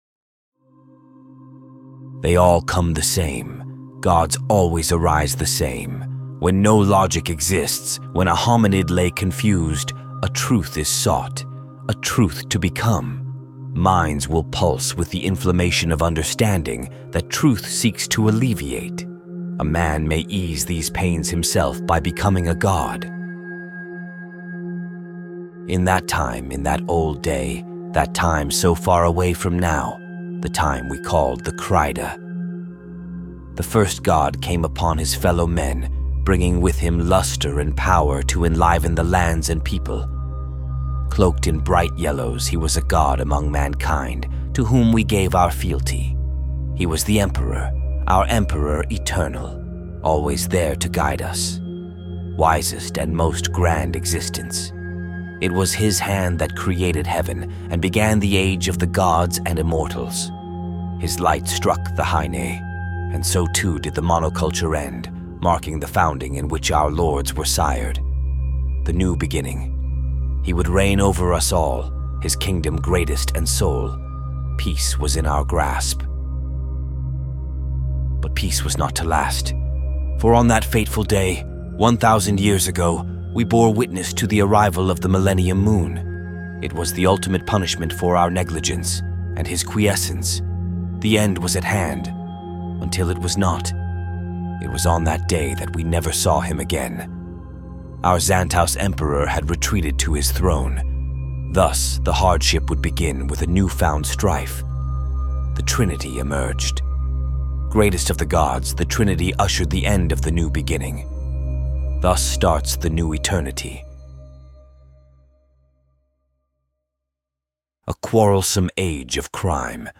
(Opening Narration)